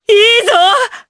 Morrah-Vox_Happy4_jpb.wav